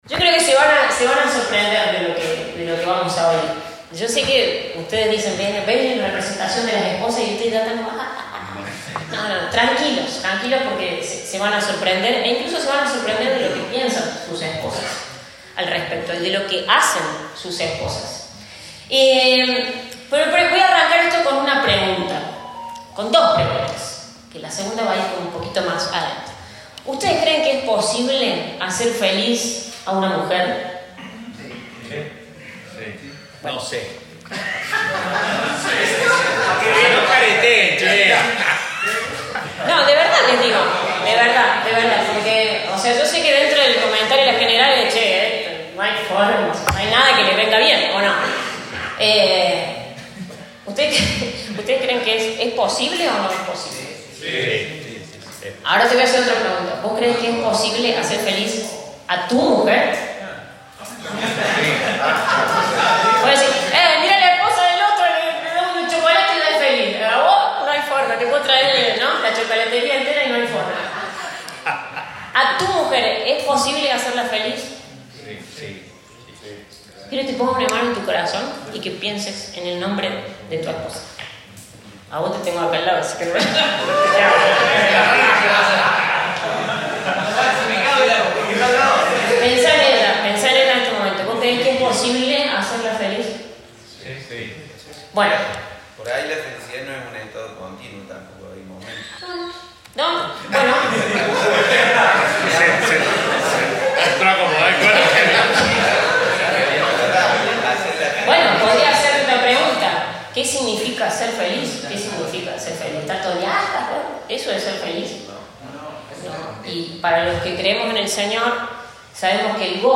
Compartimos el mensaje destinado a los hombres de La Misión, en el marco del retiro 2023.